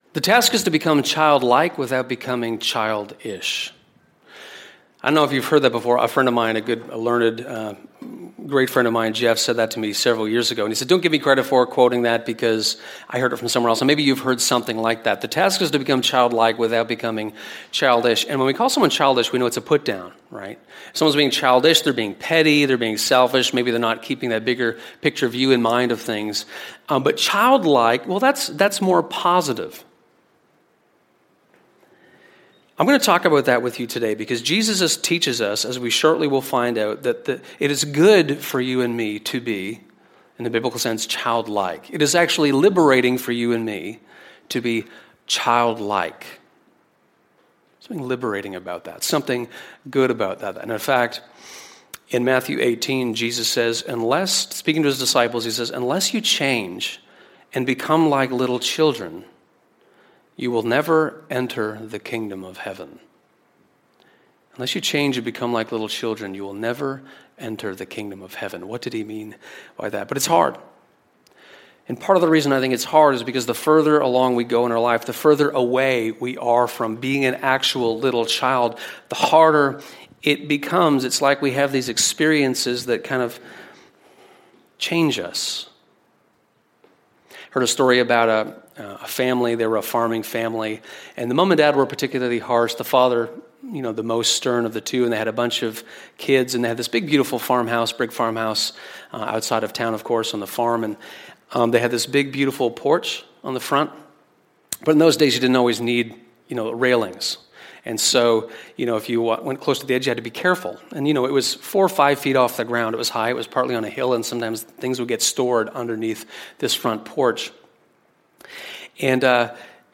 Unless you become like little children [Sermon]